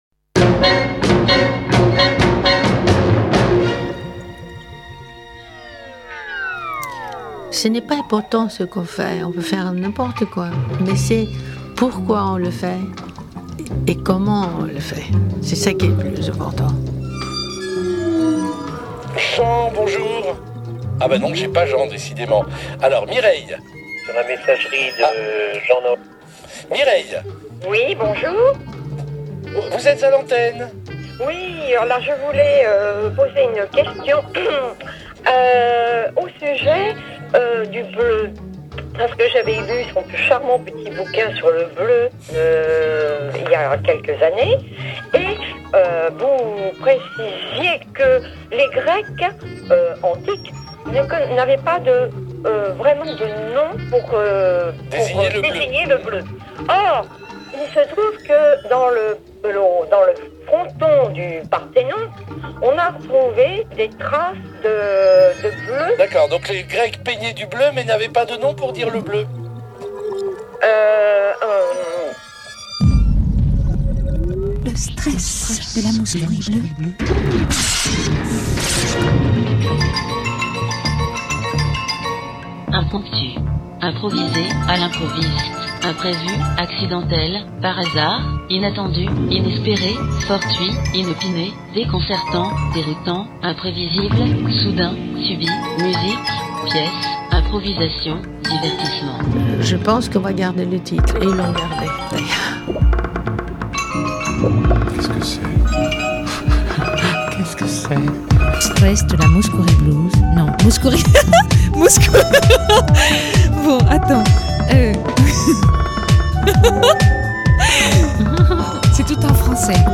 Retrouvailles et fin de cycle. Ultime numéro où le montage en écriture automatique est plus que jamais guidé par le off et le lâcher-prise . diffusion originale : radio Pulsar le 28.11.2010 à partir de 22h et quelques secondes. spécificités : regarde le son tomber format : 30' minimum, 60' maximum... surprise ! genre : humain --------